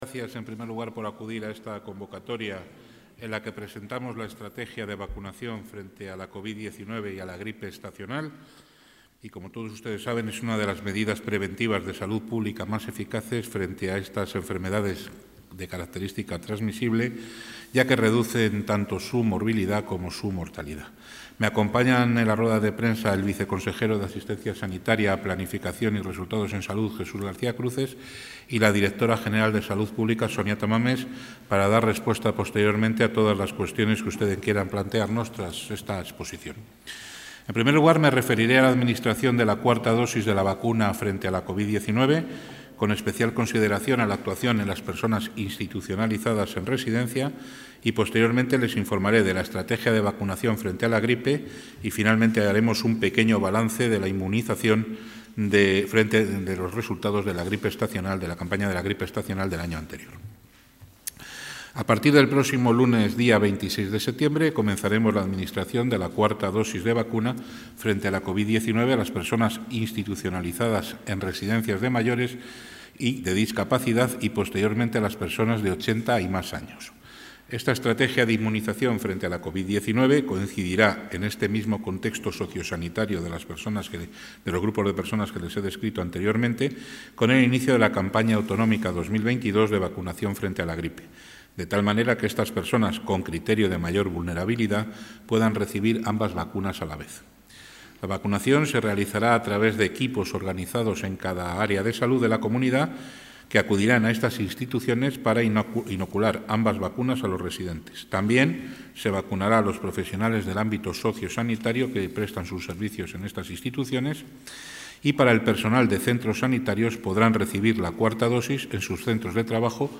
Intervención del consejero de Sanidad.